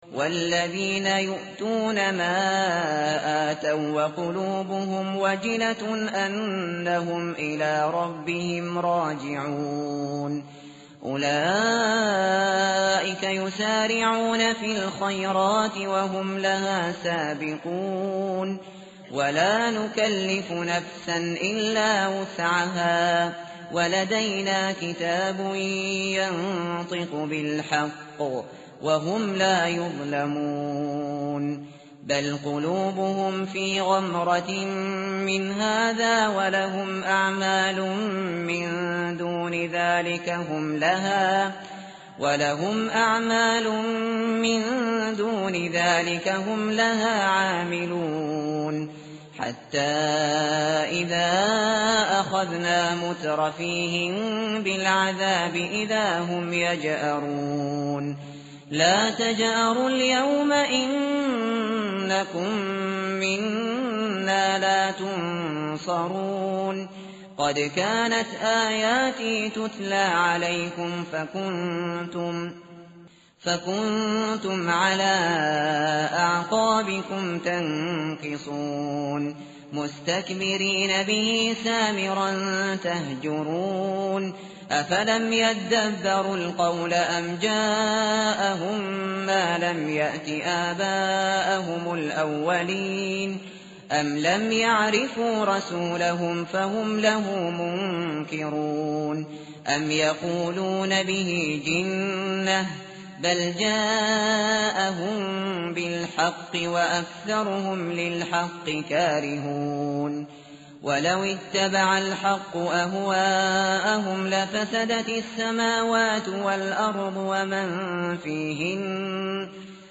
متن قرآن همراه باتلاوت قرآن و ترجمه
tartil_shateri_page_346.mp3